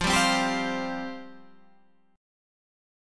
Fsus2 chord